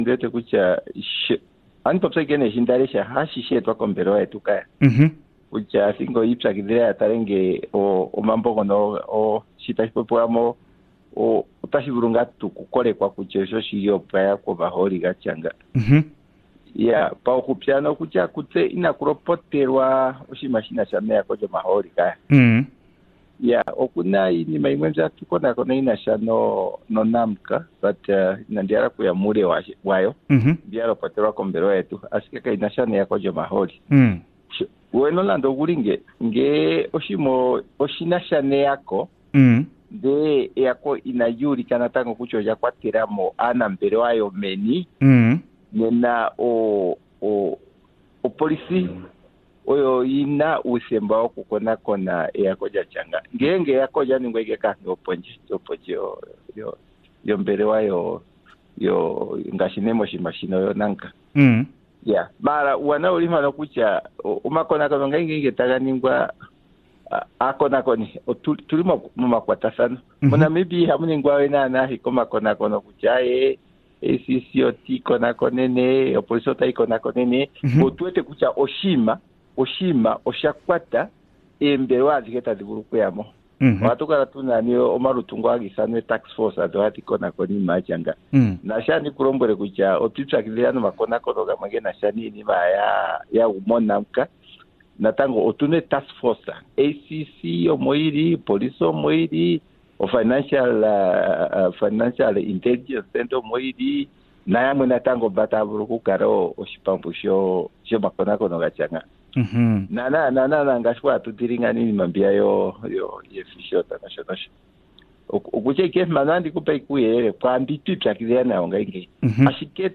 18 Mar Interview with Director of the Ant-Corruption Commission of Namibia, Mr. Paulus Noa